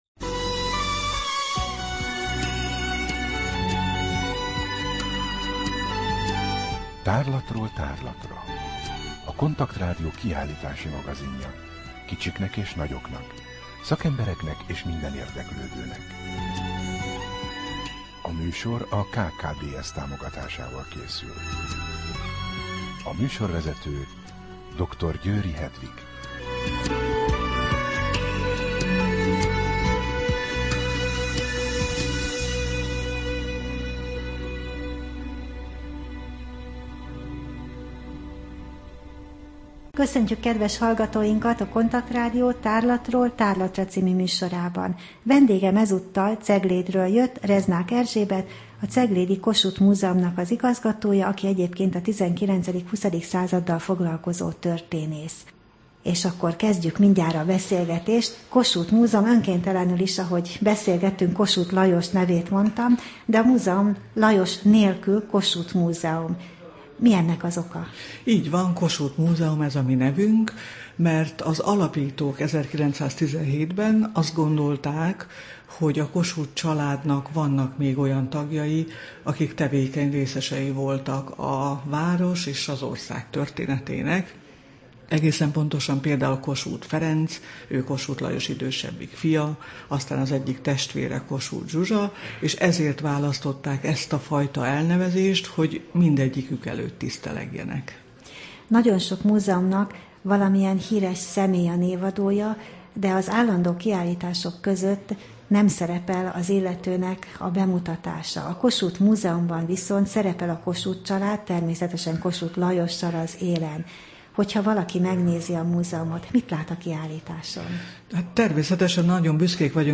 Rádió: Tárlatról tárlatra Adás dátuma: 2012, November 12 Tárlatról tárlatra / KONTAKT Rádió (87,6 MHz) 2012. november 12.